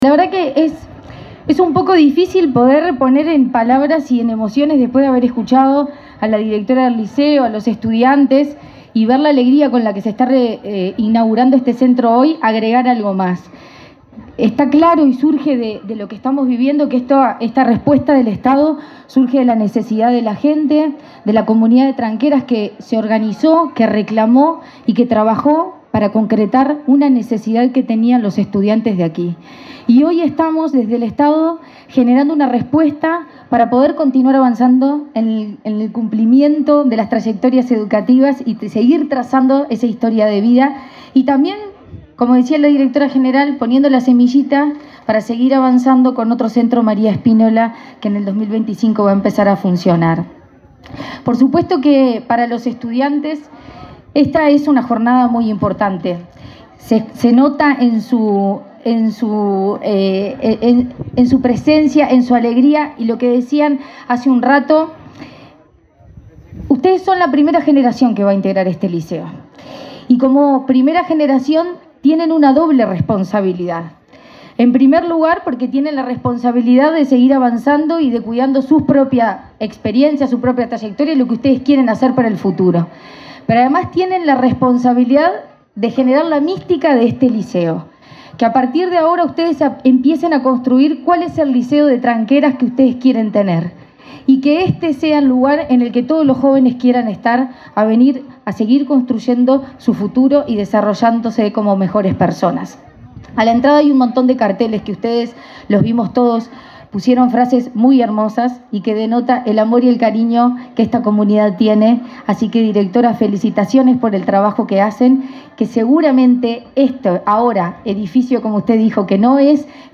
Palabras de la presidenta de la ANEP, Virginia Cáceres
Palabras de la presidenta de la ANEP, Virginia Cáceres 07/03/2024 Compartir Facebook X Copiar enlace WhatsApp LinkedIn La presidenta de la Administración Nacional de Educación Pública (ANEP), Virginia Cáceres, participó, este jueves 7 en Tranqueras, Rivera, en la inauguración de un liceo.